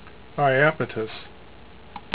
"eye AP i tus" ) is the seventeenth of Saturn's known satellites and the third largest: